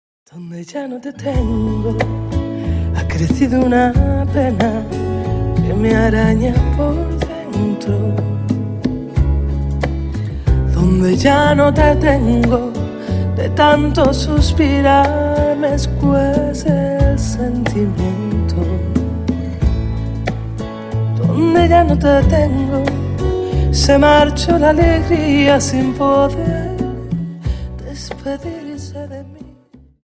Rumba 24 Song